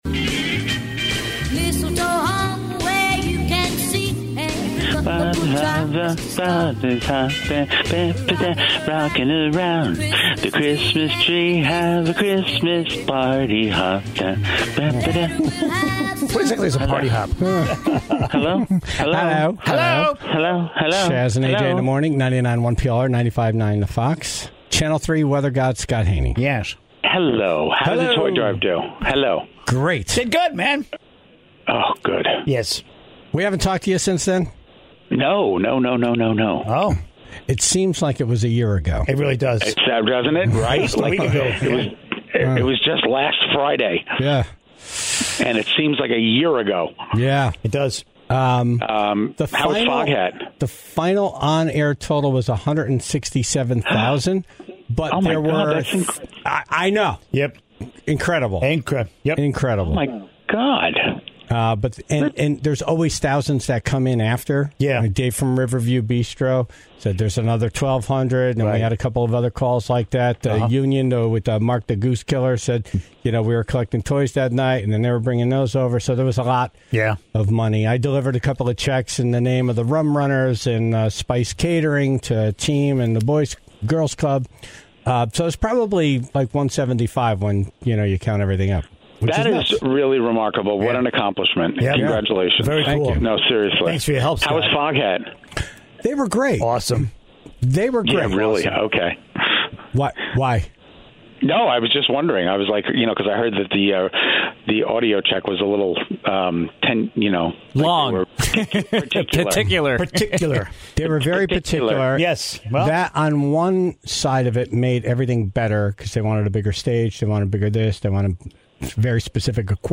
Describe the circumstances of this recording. The best of the worst from 2023, a countdown of the five funniest flubbles from a year of radio. (11:53)